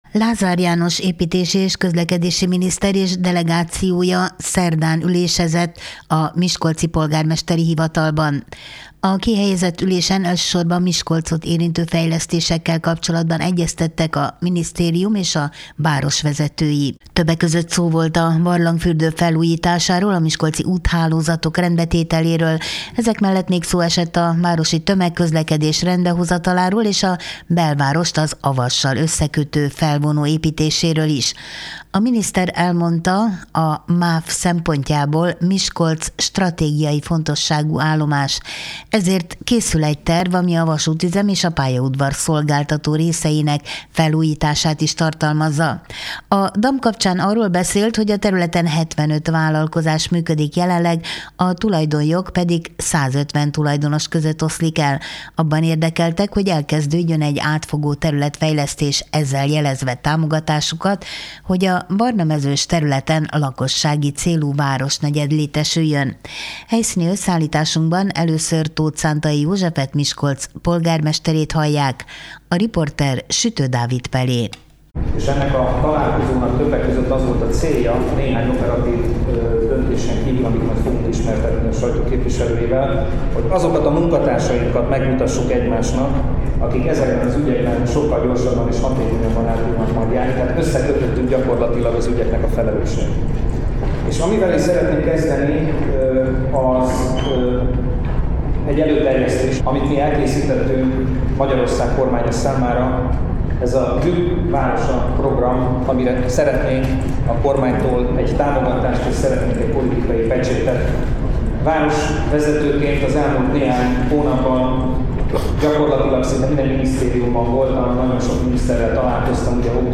15-3_lazar_janos_sajto.mp3